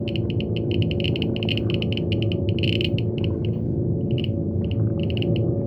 nuclear-reactor-2.ogg